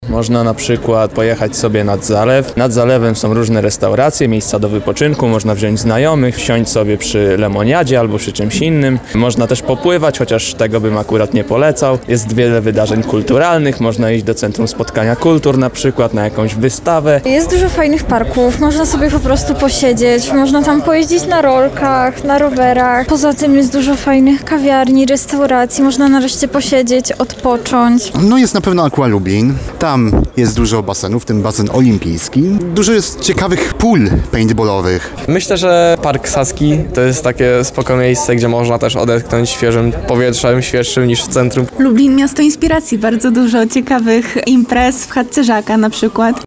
sonda – wakacje